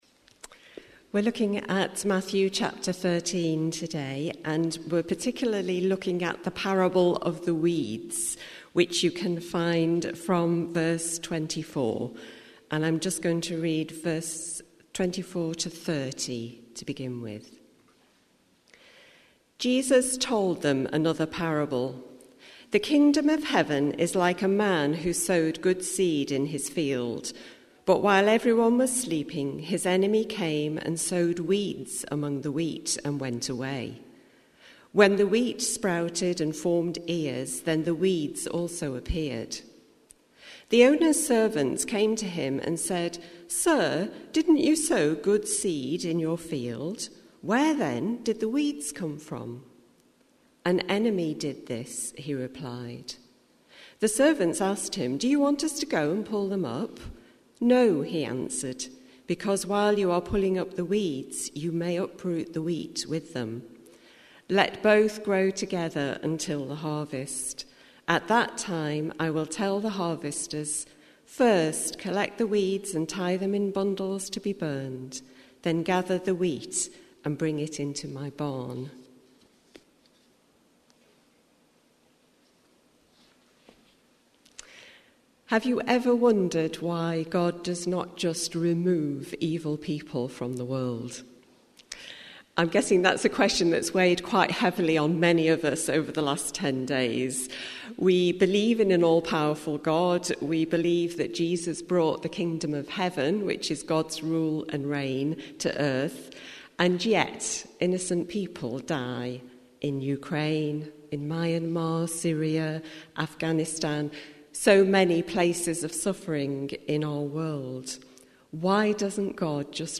NBC-Early-Service-6th-March-2022.mp3